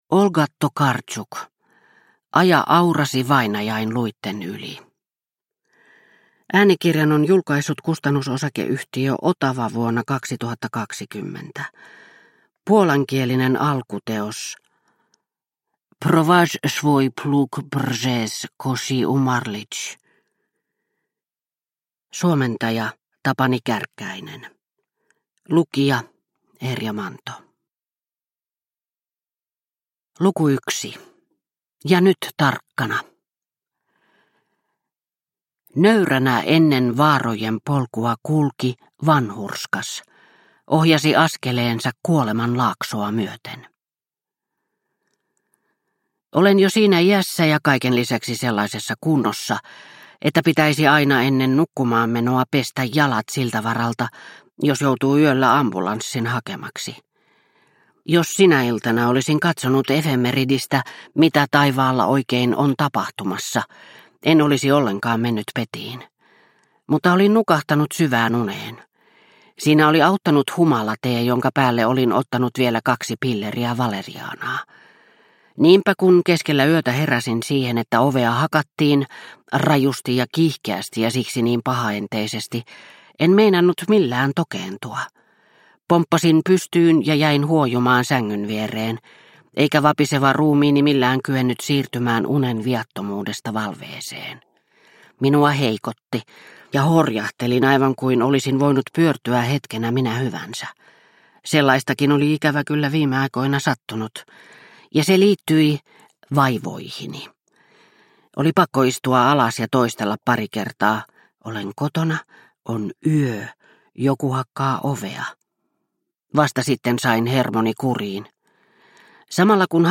Aja aurasi vainajain luitten yli – Ljudbok – Laddas ner